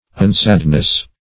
Unsadness \Un*sad"ness\, n.